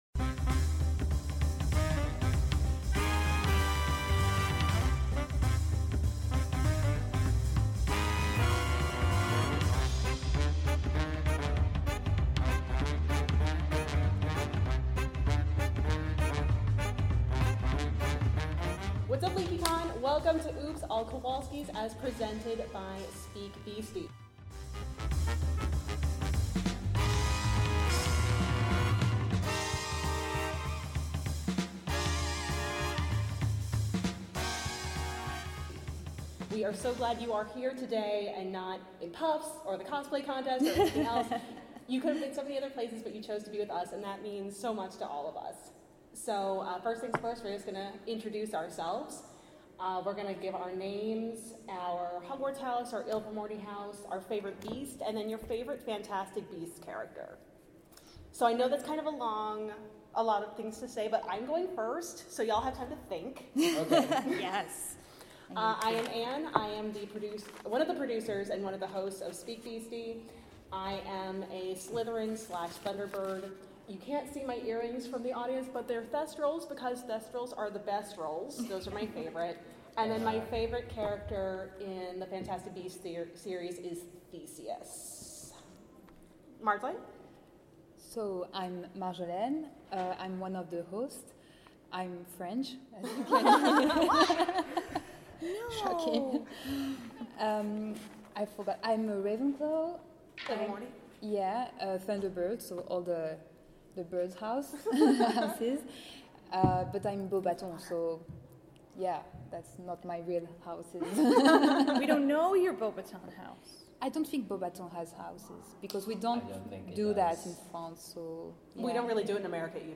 We're live from LeakyCon!
Dumbledougal > Dumbleron The hosts each bring a theory, rank them, and the audience ranks them!